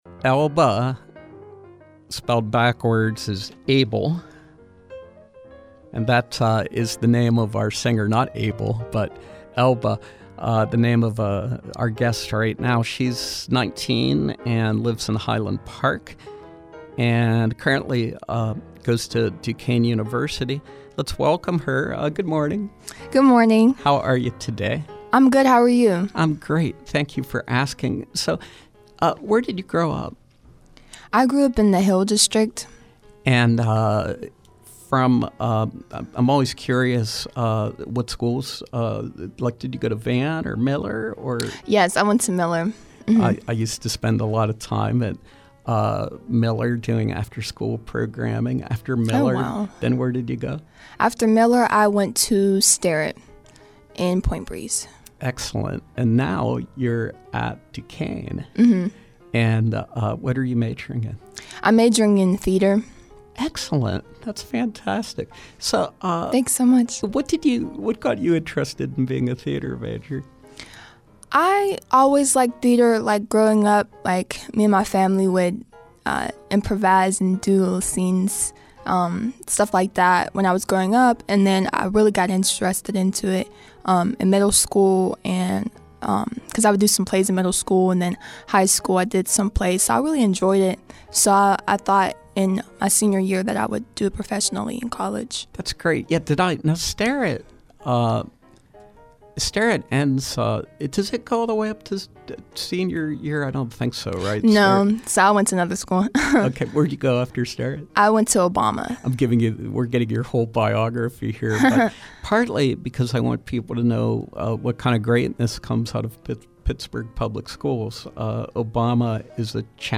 Live music